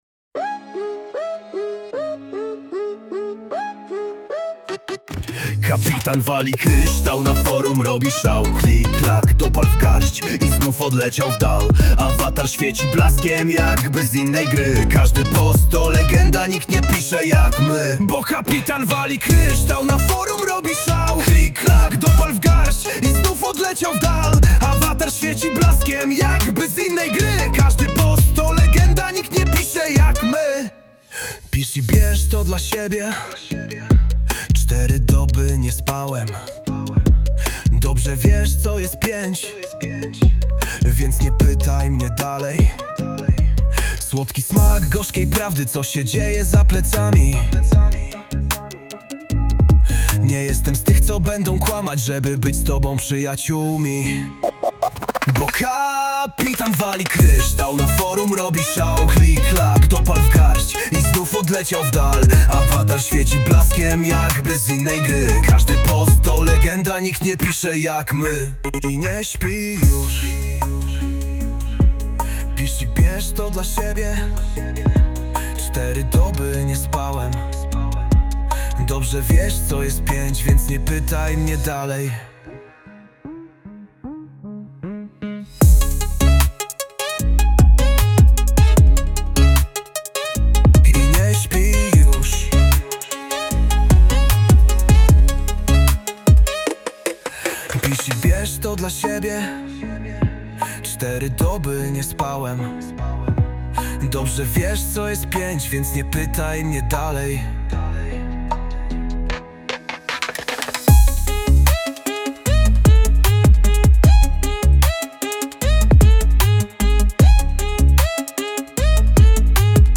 Ma chłopak talent do generowania piosenek ai.